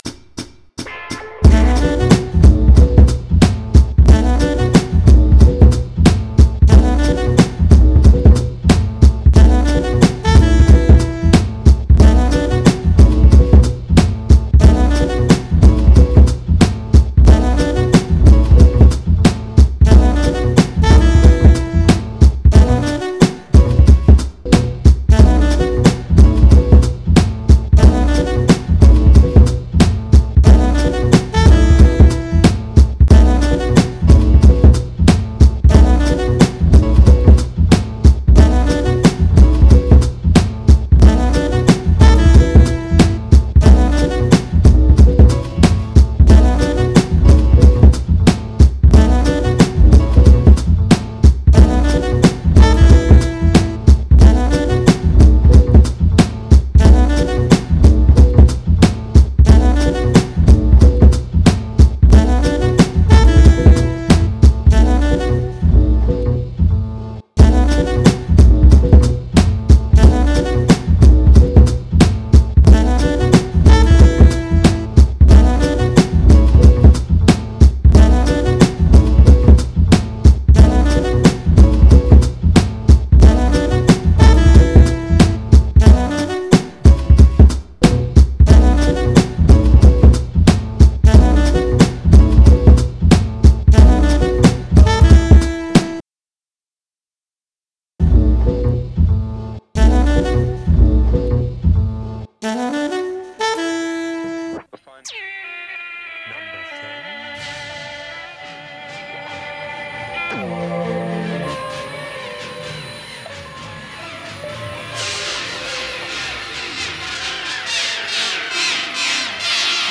Underground Hip Hop
Nu-old beats re-engineered.